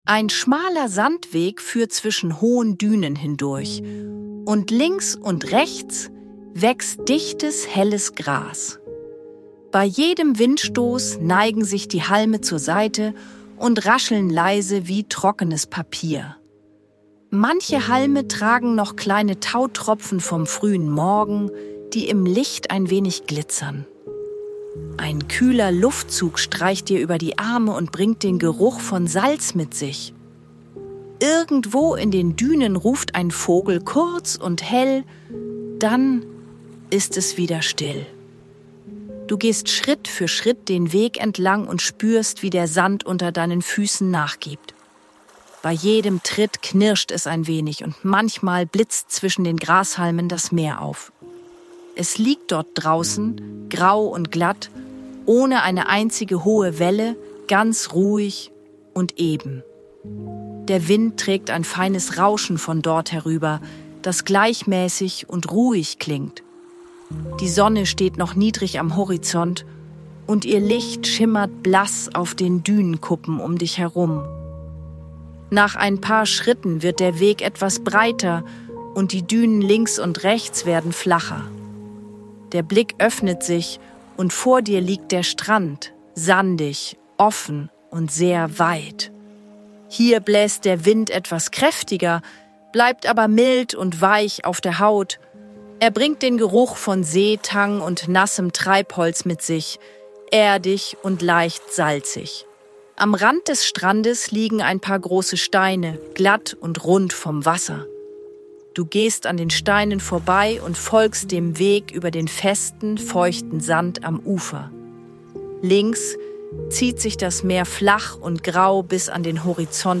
Sanfte Fantasiereisen mit leiser Hintergrundmusik – zum Malen und kreativen Entspannen